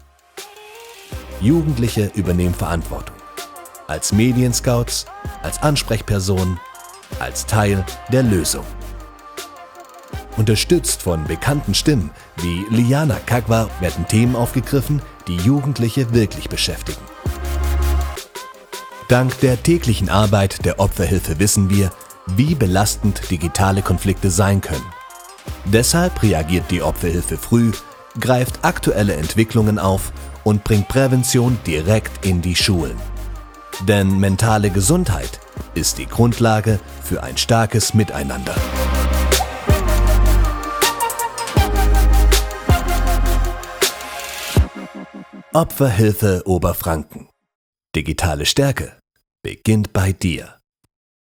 Voiceover & Sprecher
Voiceover /// Eventdokumentation Opferhilfe Oberfranken
Als Sprecher für Voiceover, Werbung, Imagefilme, Eventvideos, Hörbücher und Hörspiele bringe ich Inhalte authentisch und emotional auf den Punkt – ruhig oder dynamisch, seriös oder lebendig, passend zu deinem Projekt.